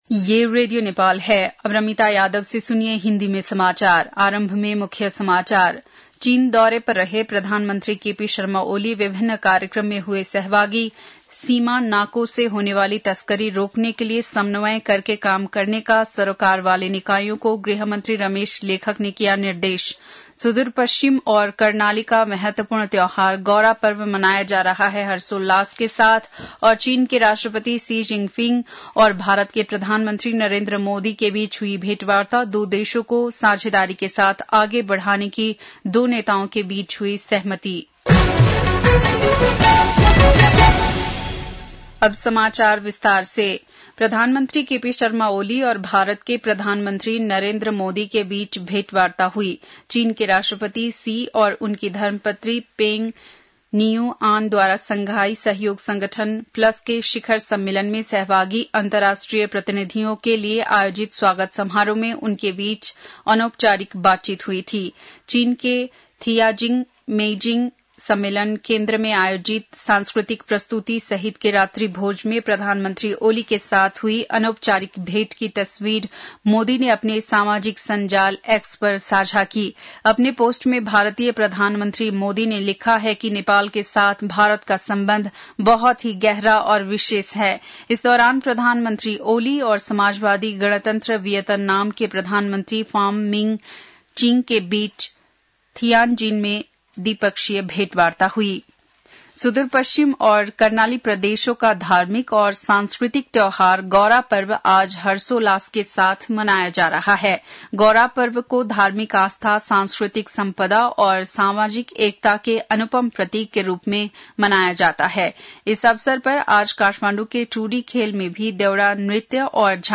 बेलुकी १० बजेको हिन्दी समाचार : १५ भदौ , २०८२
10-pm-hindi-news-.mp3